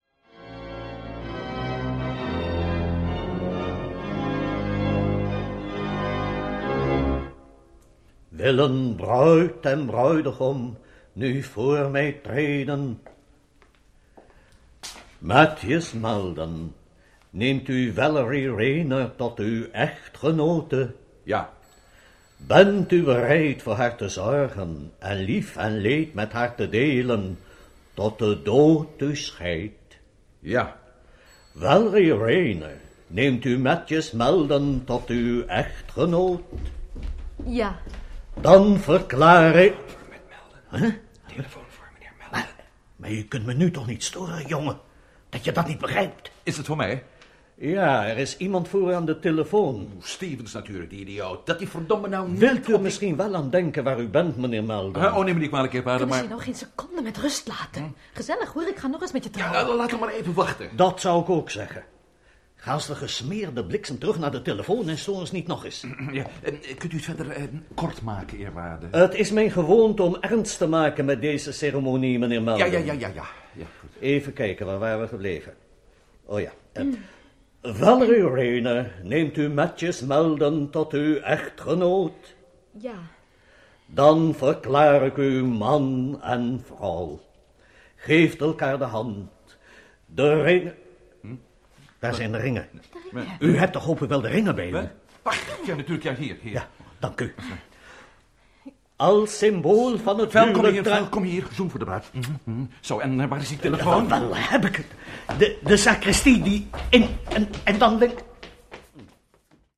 Rol(len) in de hoorspelreeks: – Prometheus XIII – Priester & scheepsarts